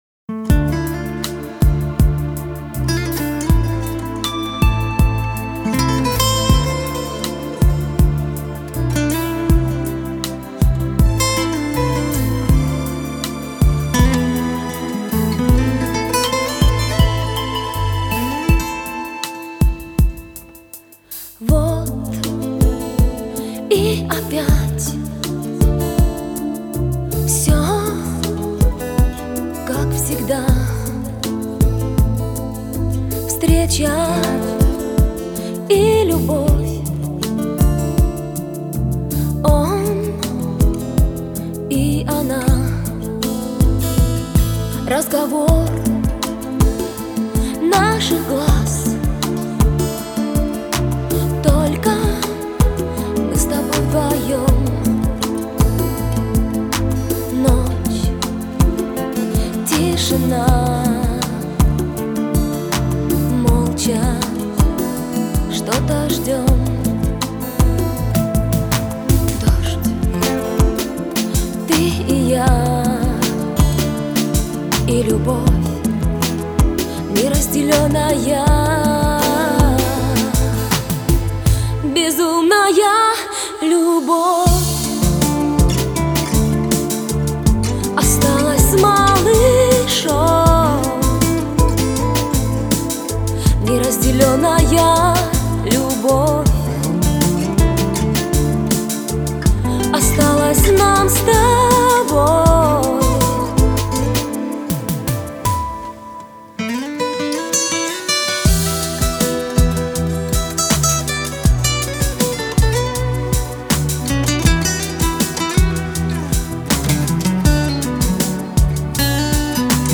Жанр: Electronic, Pop, Rock